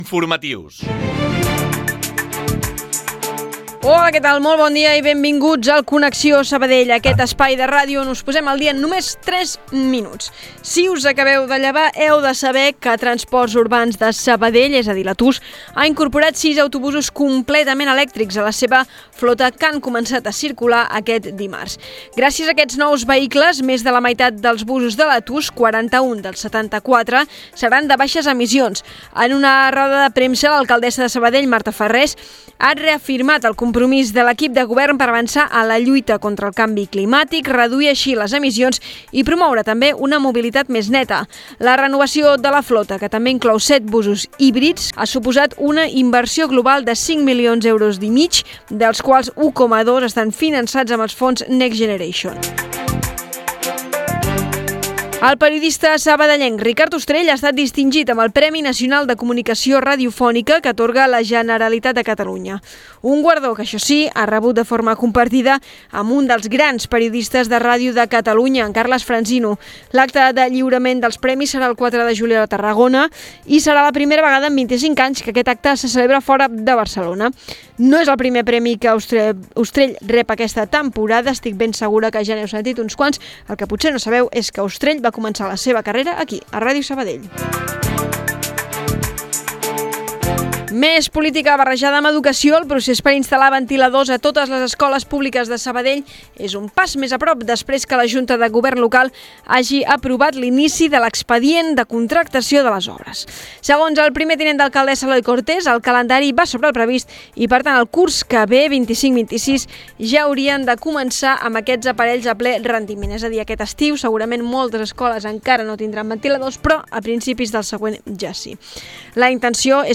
Entrevista al cantant Macaco